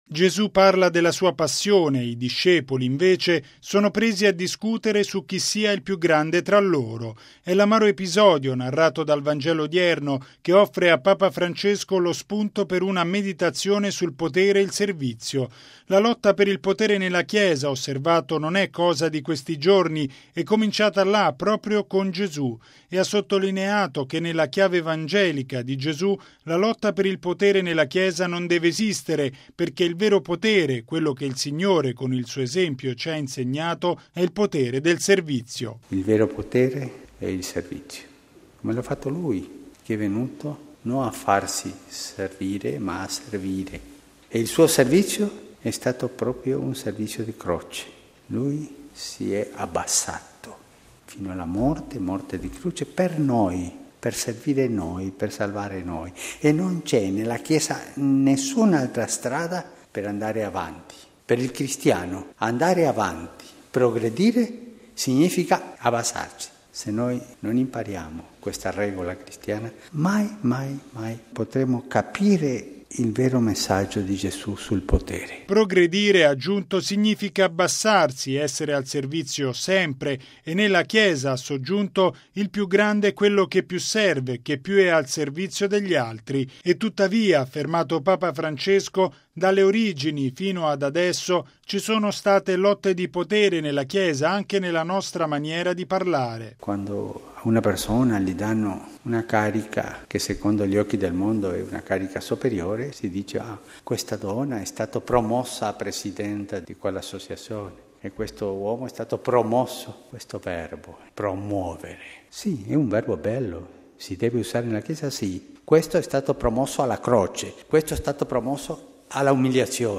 ◊   Per un cristiano, progredire significa abbassarsi come ha fatto Gesù. E’ quanto sottolineato da Papa Francesco nella Messa di stamani alla Casa Santa Marta.